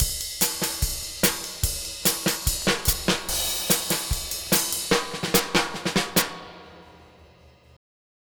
Twisting 2Nite 2 Drumz.wav